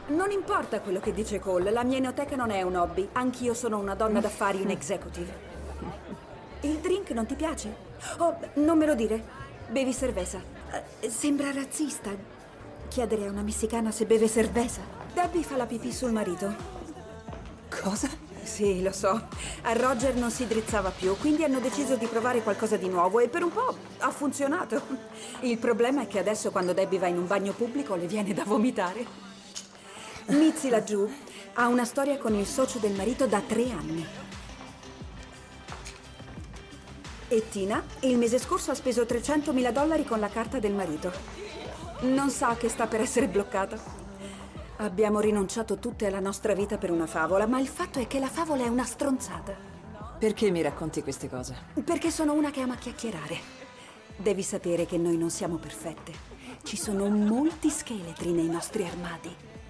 telefilm "Regina del Sud", in cui doppia Molly Burnett.